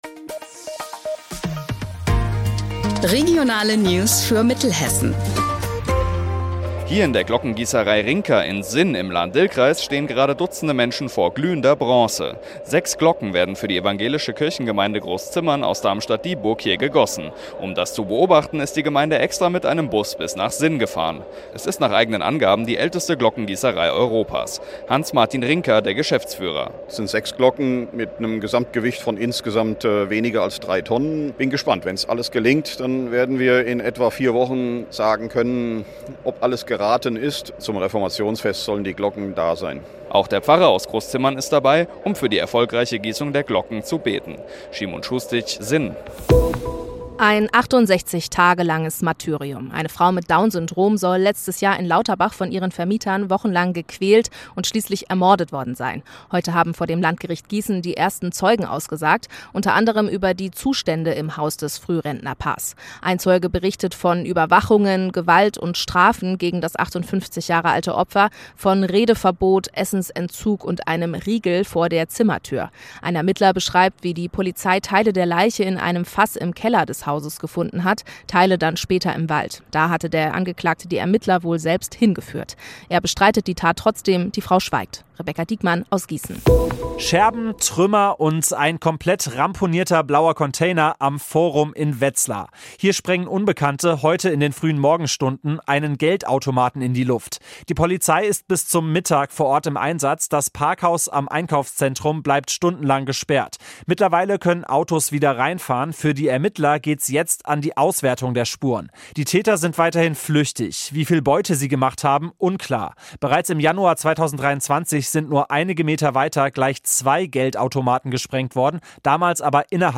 Mittags eine aktuelle Reportage des Studios Gießen für die Region